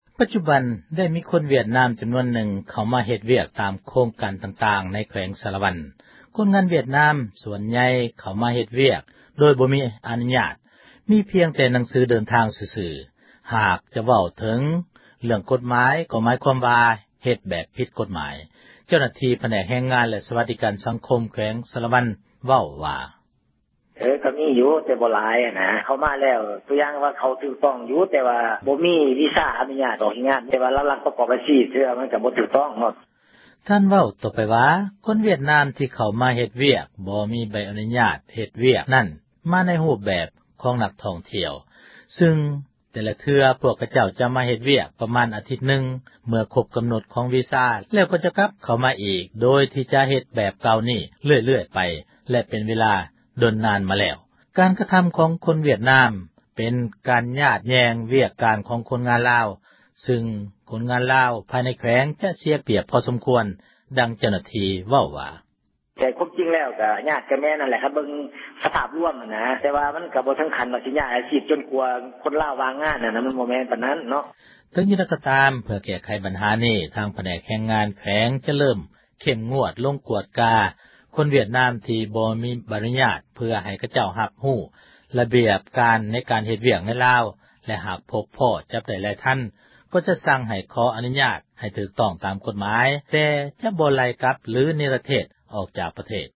ເຈົ້າໜ້າທີ່ ຜແນກແຮງງານ ແລະ ສວັດດີການ ສັງຄົມ ແຂວງ ສາຣະວັນ ເວົ້າວ່າ: